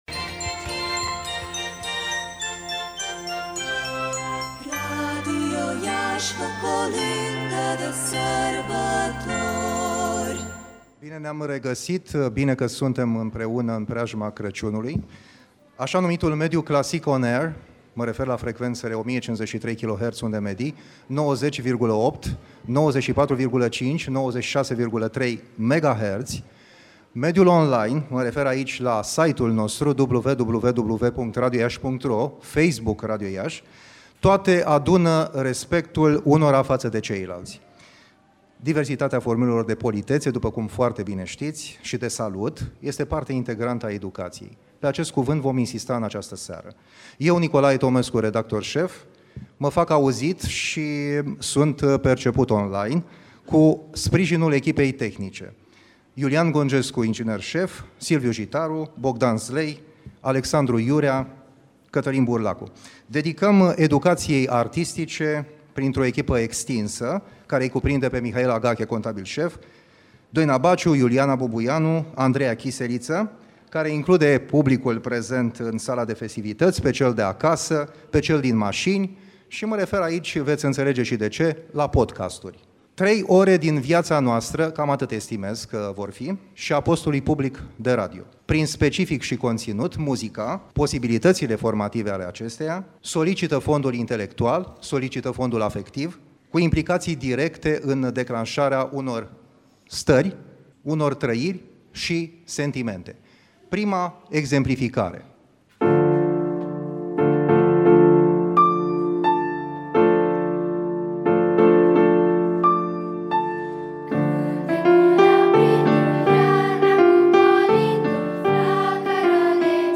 (Multimedia) Spectacol de colinde la Radio Iași / post eveniment - Radio Iaşi – Cel mai ascultat radio regional - știri, muzică și evenimente
având drept loc de desfășurare „ Bloc Producție Radio Iași ” și reunind aproape 150 de artiști